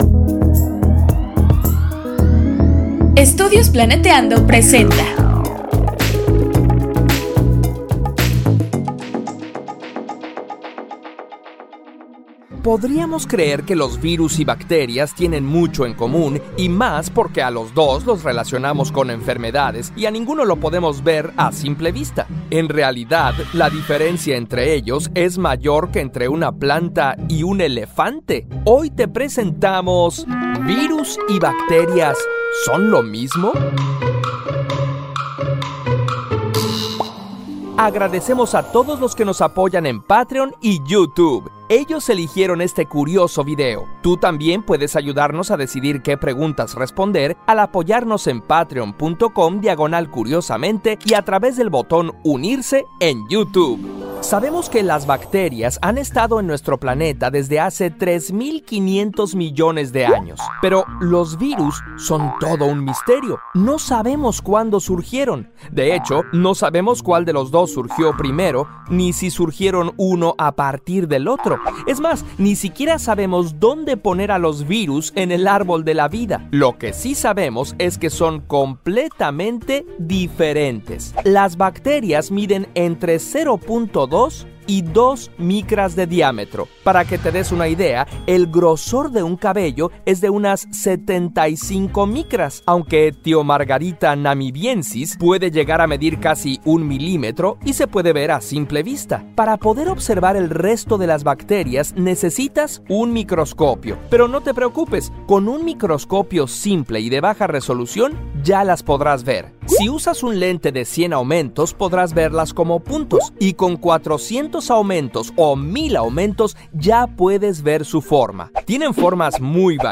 Voz
Música